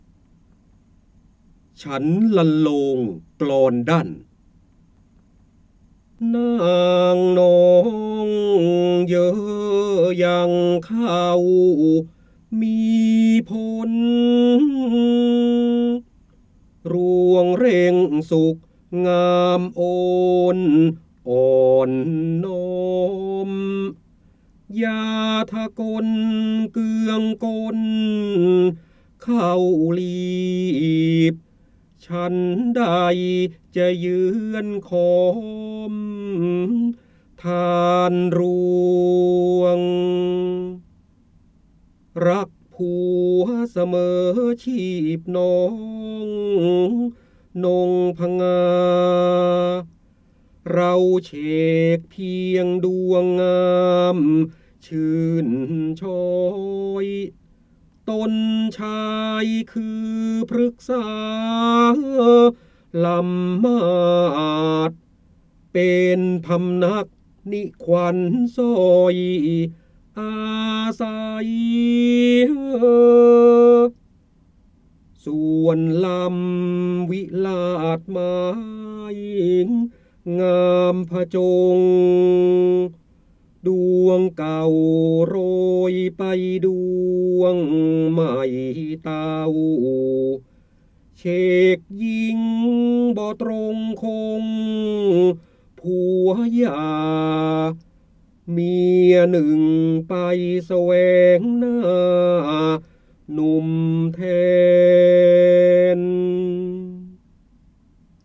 เสียงบรรยายจากหนังสือ จินดามณี (พระโหราธิบดี) ฉันทลรรโลงกลอนดั้น
คำสำคัญ : พระโหราธิบดี, ร้อยกรอง, พระเจ้าบรมโกศ, จินดามณี, ร้อยแก้ว, การอ่านออกเสียง
ลักษณะของสื่อ :   คลิปเสียง, คลิปการเรียนรู้